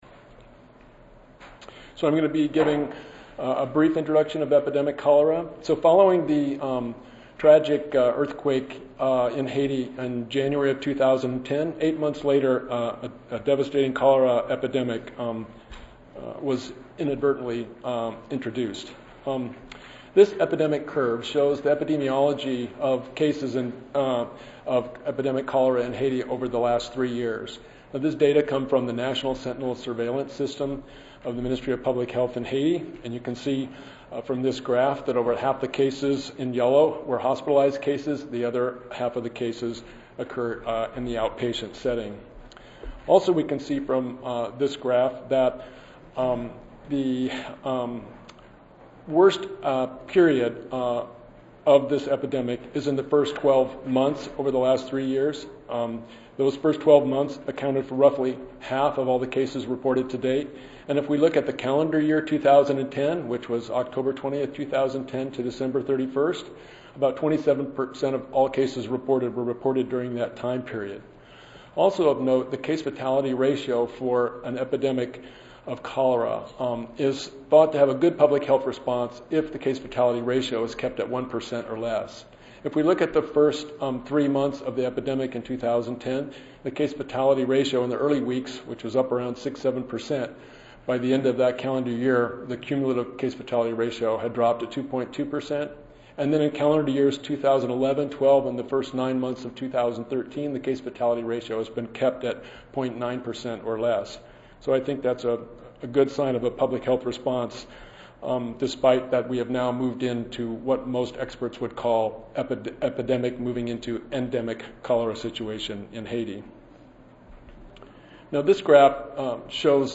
141st APHA Annual Meeting and Exposition